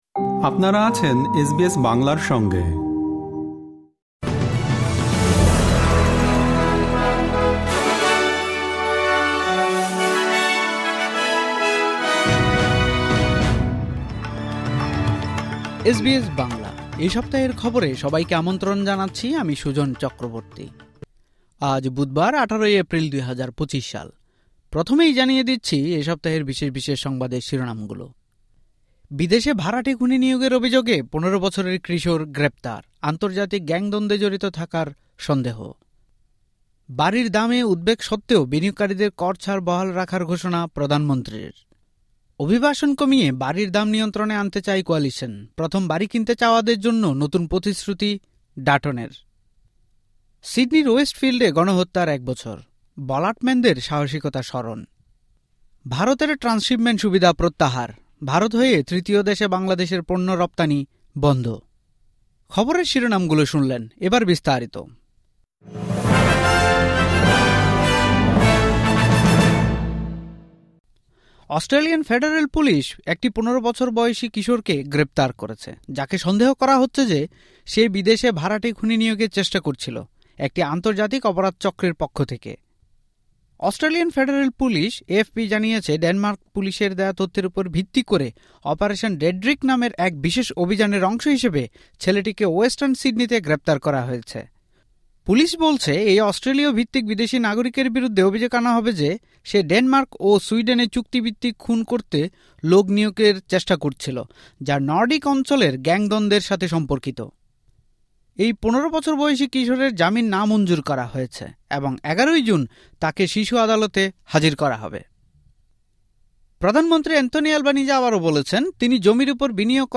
অস্ট্রেলিয়ার এ সপ্তাহের জাতীয় ও আন্তর্জাতিক গুরুত্বপূর্ণ সংবাদ শুনতে উপরের অডিও-প্লেয়ারটিতে ক্লিক করুন।